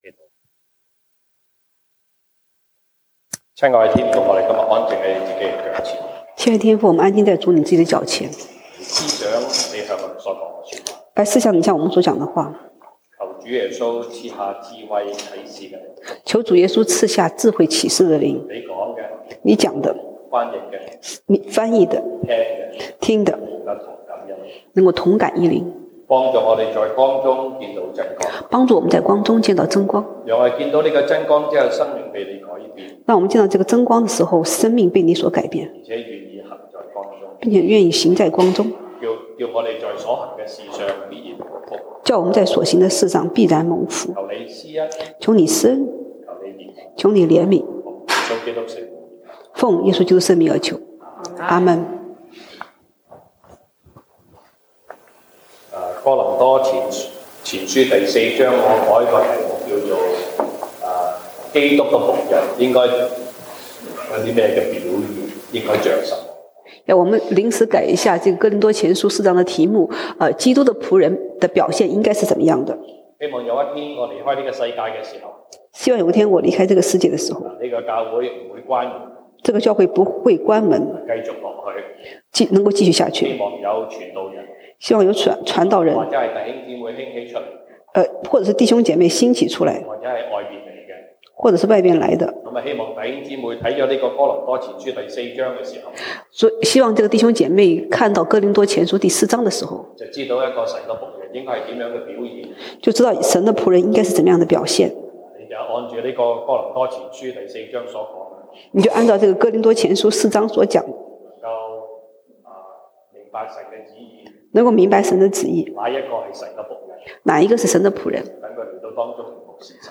1 Corinthians Passage: 歌林多前書 1 Corinthians 4:1-7 Service Type: 西堂證道(粵語/國語) Sunday Service Chinese Topics